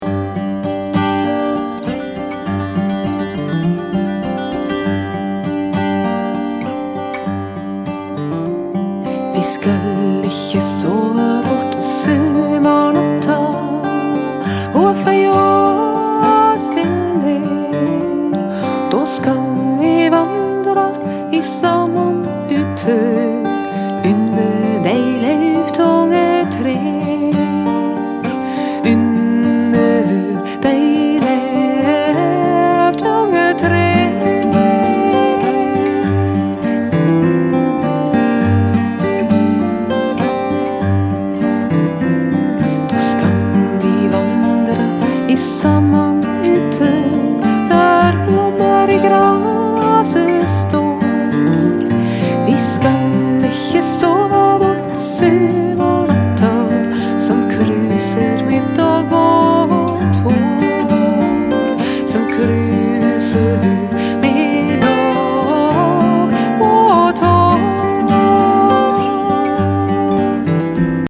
同時也是一位靈巧的演奏者。
從這些歌曲中，可以欣賞「新民謠」（New Acoustic）的風味。
這種音樂有一種「手工製造」的溫暖質感，深具感官上的豐富性。
這首歌用舒緩的調子唱出了這迷離的氣氛，歌手的嗓音和歌曲情境達到完美的配合，值得品味再三。